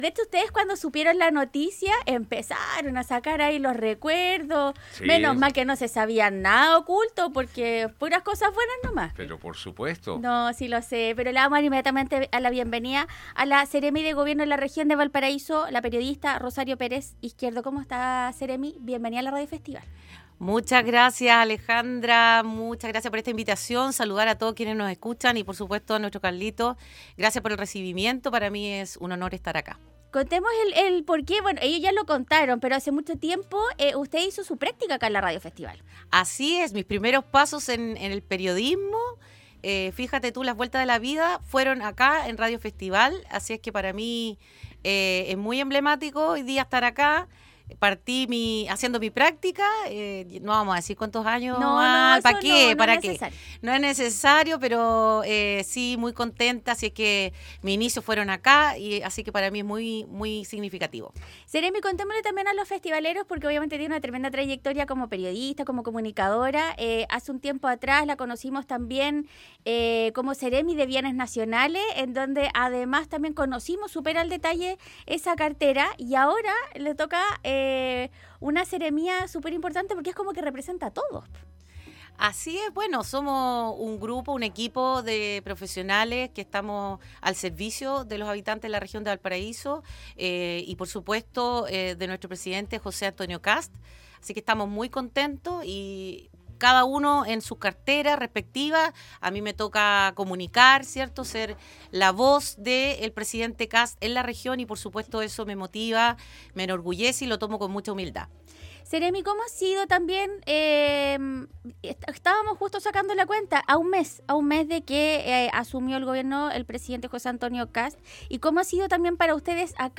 La seremi de Gobierno de la Región de Valparaíso, Rosario Pérez conversó con Radio Festival y contó detalles del fondo, así como las Escuelas Protegidas.